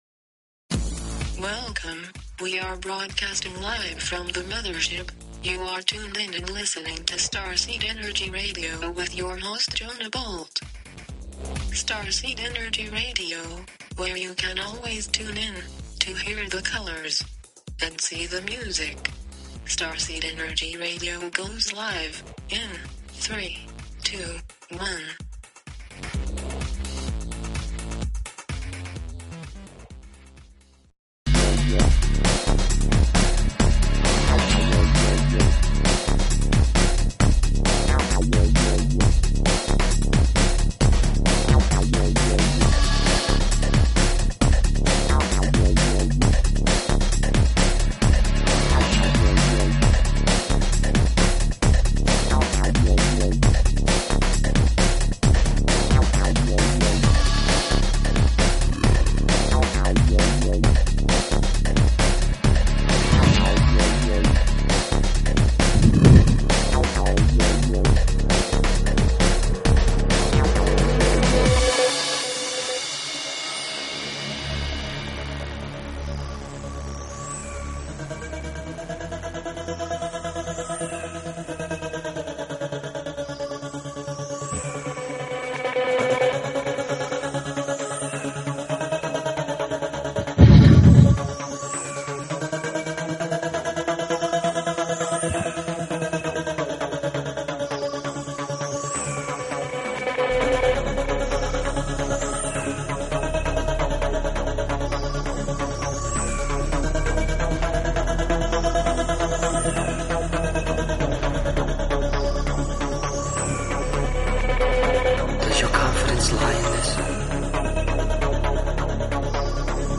Talk Show Episode, Audio Podcast, Starseed_Energy_Radio and Courtesy of BBS Radio on , show guests , about , categorized as
MUSIC - Unknown Artist - Samples from Mix